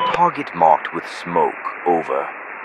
Radio-jtacSmokeOK1.ogg